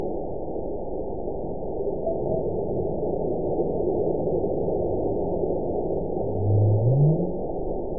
event 916680 date 01/13/23 time 18:54:53 GMT (2 years, 4 months ago) score 9.64 location TSS-AB04 detected by nrw target species NRW annotations +NRW Spectrogram: Frequency (kHz) vs. Time (s) audio not available .wav